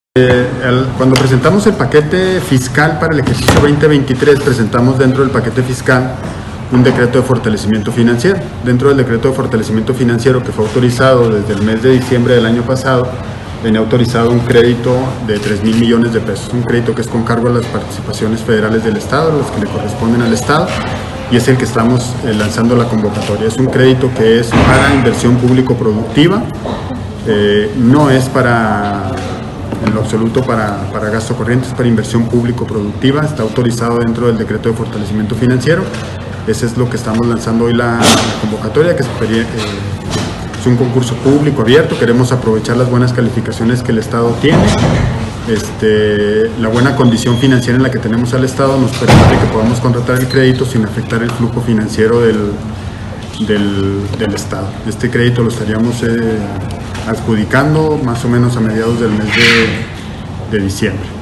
AUDIO: JOSÉ DE JESÚS GRANILLO, SECRETARÍA DE HACIENDA ESTATAL